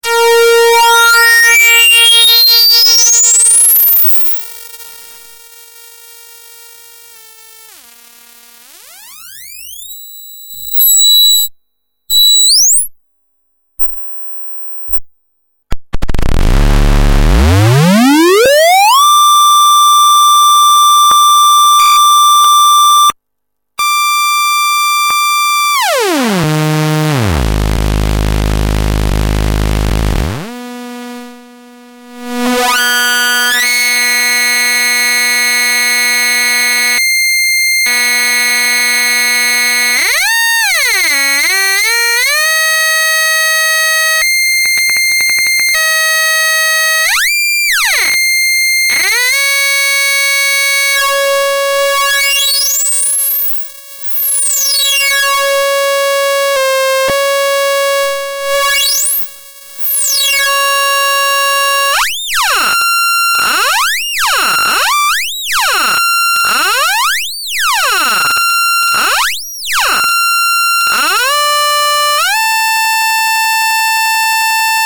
2_x_sas_synced_with_diff_waveforms_freq_sweep.mp3
2 SAS-VCO's synced together, via the sync in, and waveform out from the second VCO.